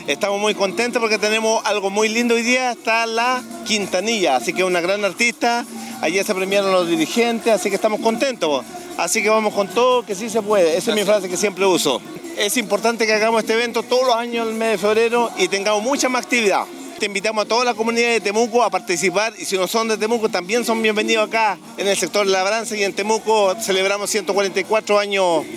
Juan-Necul-Concejal-de-Temuco.mp3